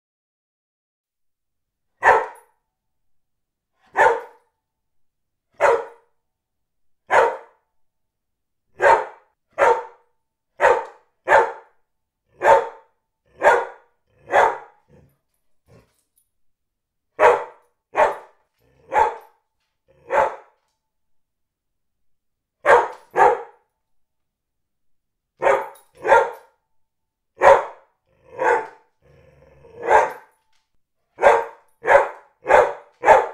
Track-22-Het-geblaf-van-een-hond.m4a